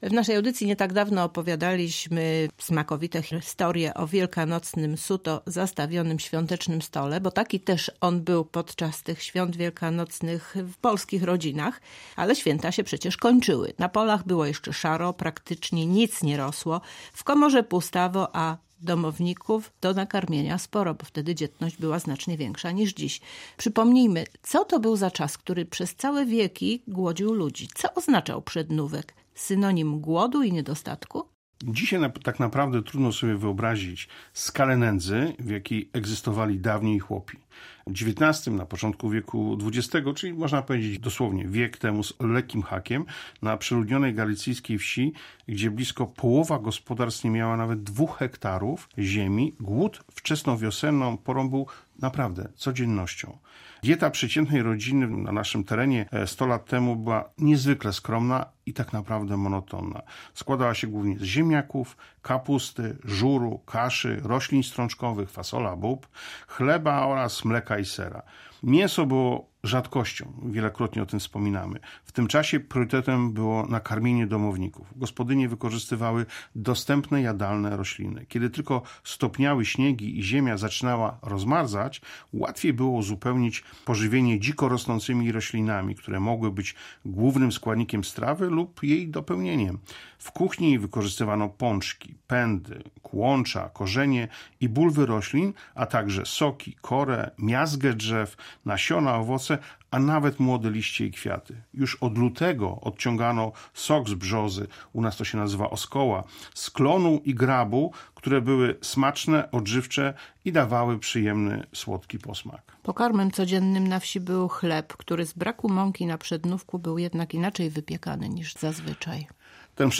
• Kulinarne pogaduchy • Polskie Radio Rzeszów